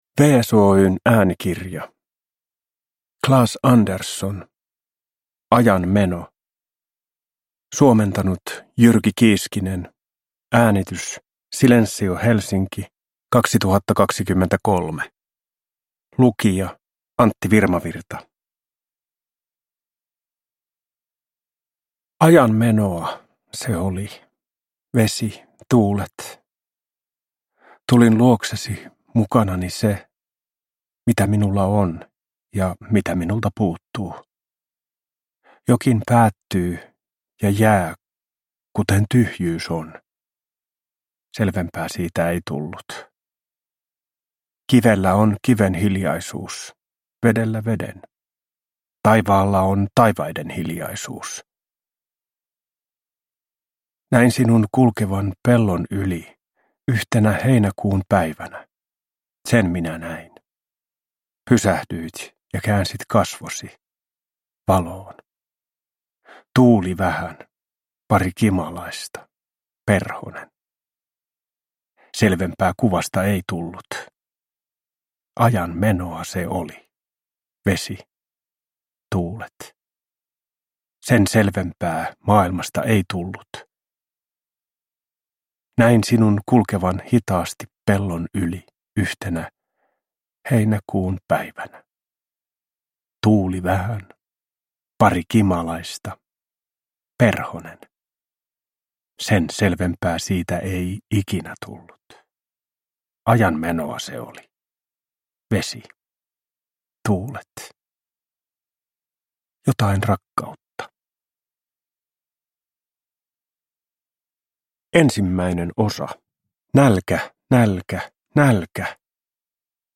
Ajan meno – Ljudbok
Lyrik Njut av en bra bok
Uppläsare: Antti Virmavirta